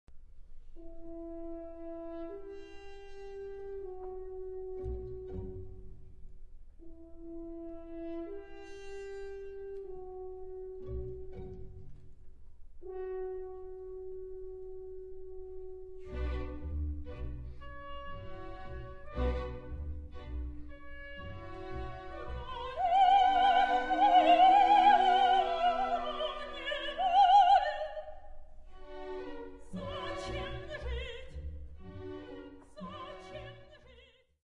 Lyudmila’s Scene and Aria